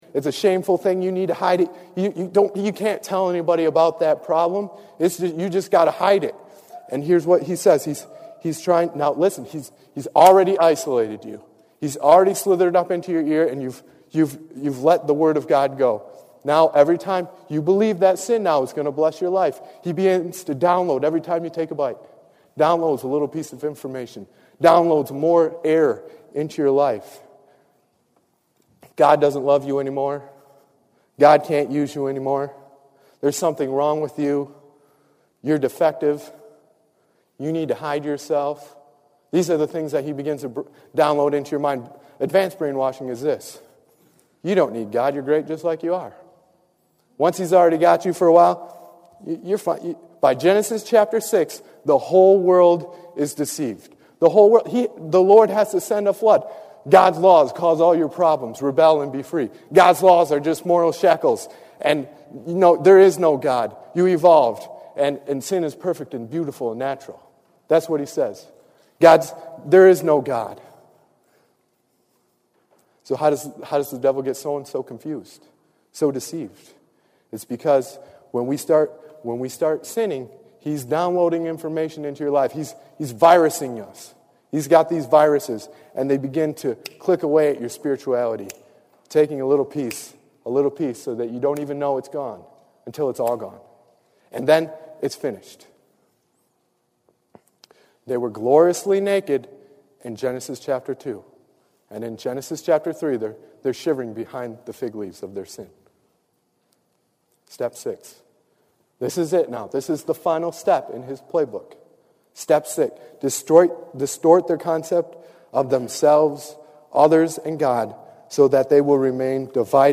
Tags: Sermon Preaching Jesus Bible God